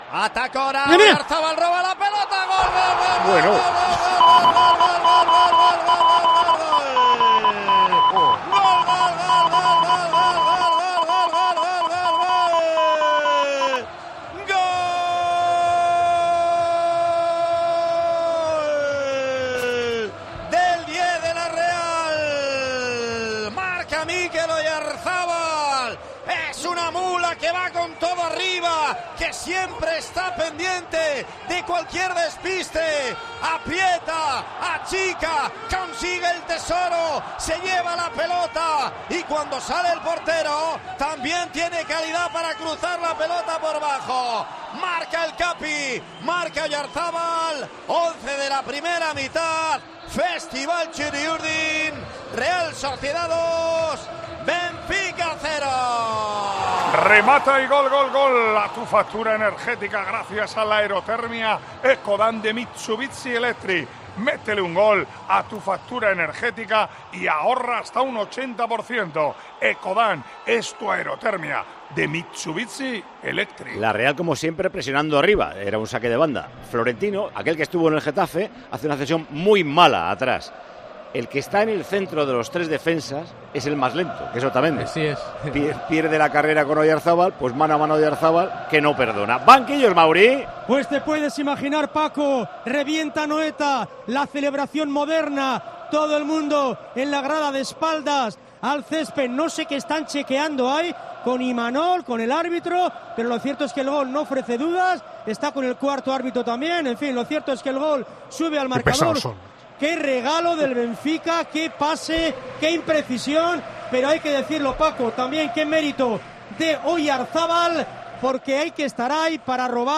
Así vivimos en Tiempo de Juego la retransmisión del Real Sociedad - Benfica